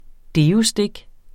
Udtale [ ˈdeːoˌsdeg ]